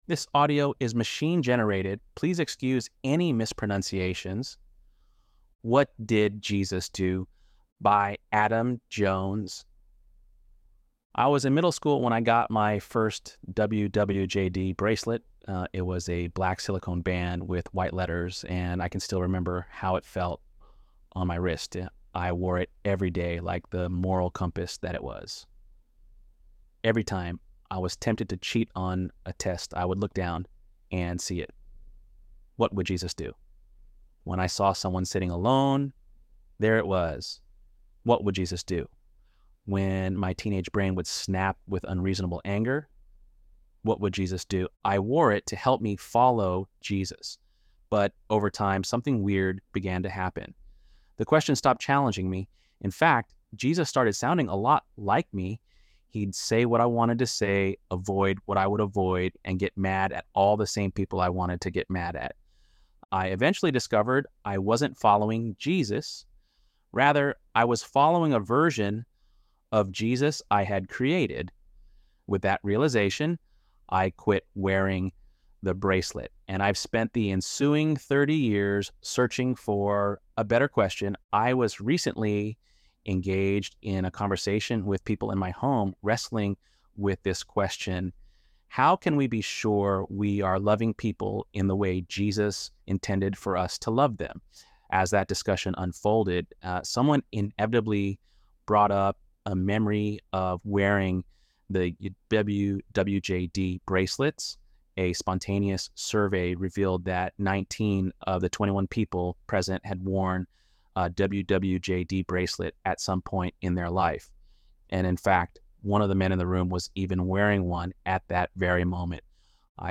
ElevenLabs_7.24_WWJD.mp3